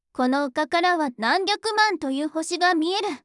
voicevox-voice-corpus
voicevox-voice-corpus / ita-corpus /もち子さん_怒り /EMOTION100_044.wav